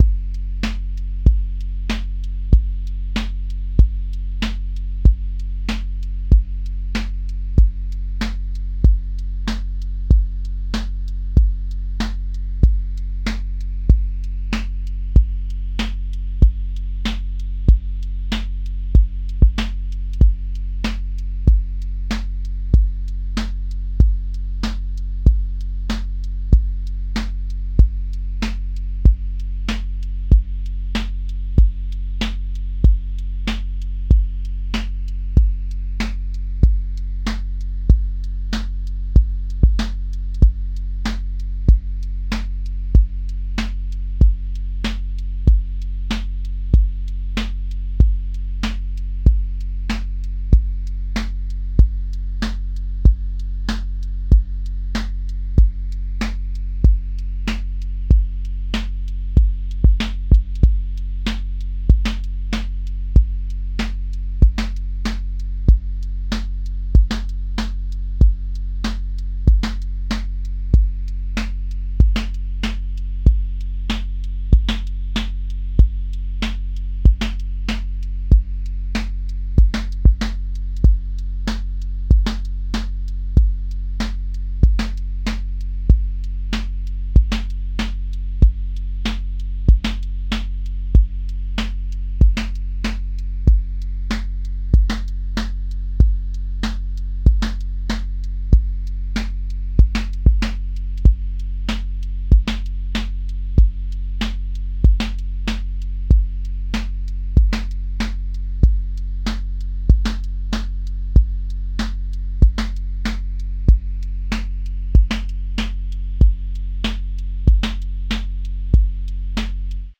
QA Listening Test boom-bap Template: boom_bap_drums_a
• voice_snare_boom_bap
• tone_warm_body
• texture_vinyl_hiss
A 120-second boom bap song with a lifted loop section, a stripped verse section, a variant bridge, and a clear outro return. Use recurring drum, sub, and counter-rhythm patterns that recombine differently by section so the form feels like a song, not one loop.